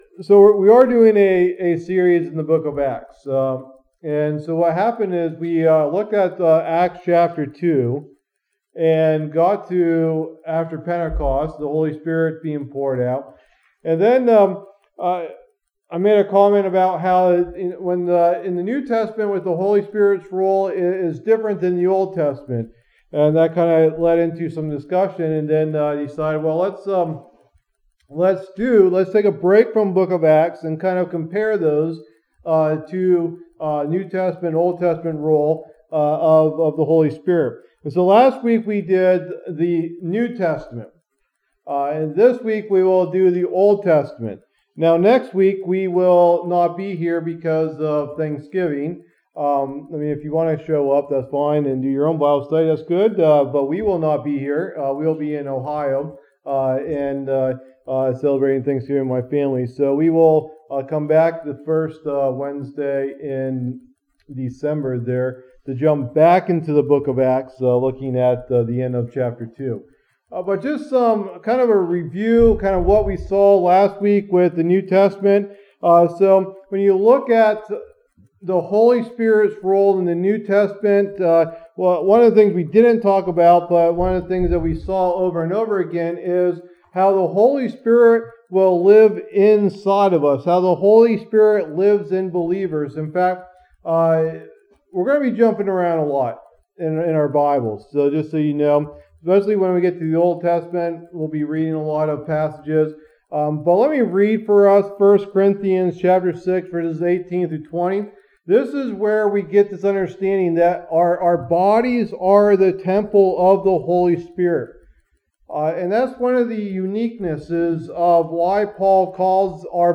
Study #8 in the Book of Acts Bible Study